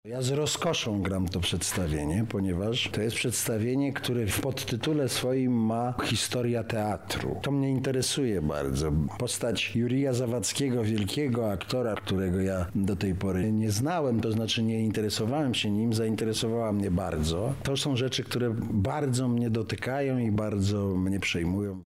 Kilka słów o kreowaniu swojej postaci powiedział Adamem Ferency.